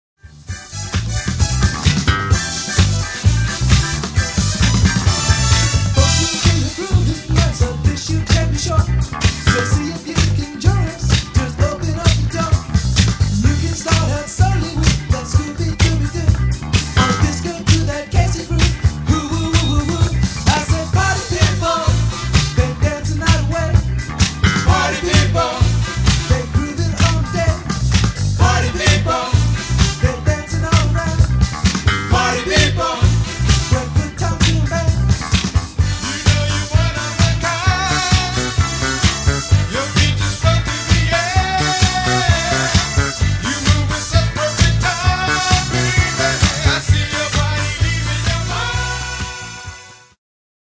Genere:   Disco | Soul | Funky